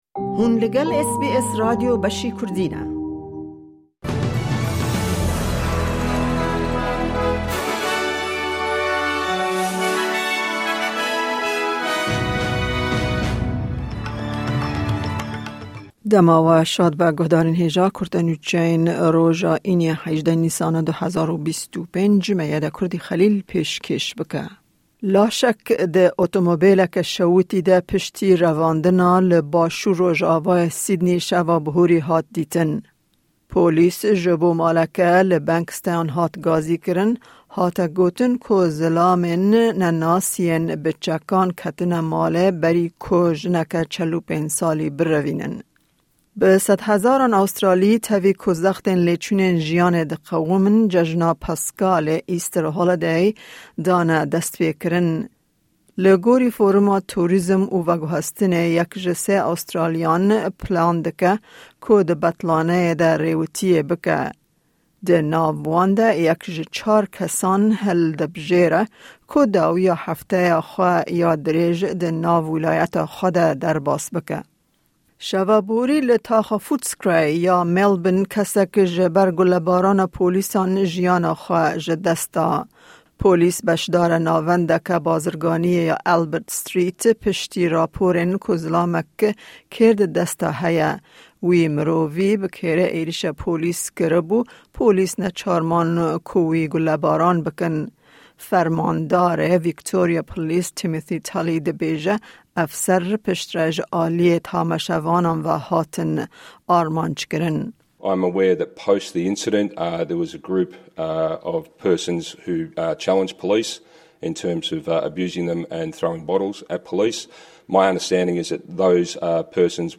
Kurte Nûçeyên roja Înî 18î Nîsana 2025